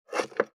484,切る,包丁,厨房,台所,野菜切る,咀嚼音,ナイフ,調理音,まな板の上,料理,